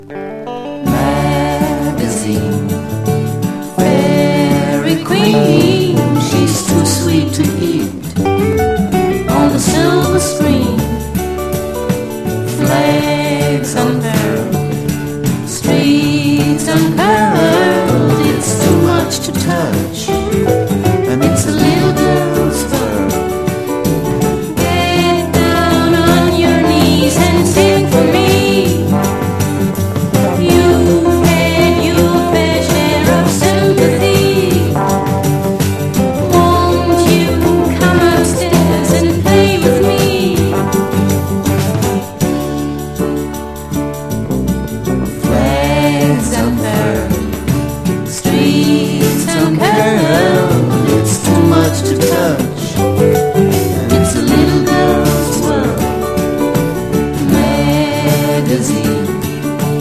ROCK / 70'S / PSYCHEDELIC / COUNTRY ROCK / ACID FOLK